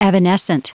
Prononciation du mot evanescent en anglais (fichier audio)
Prononciation du mot : evanescent